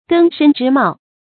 根深枝茂 注音： ㄍㄣ ㄕㄣ ㄓㄧ ㄇㄠˋ 讀音讀法： 意思解釋： 比喻基礎牢固，就會興旺發展。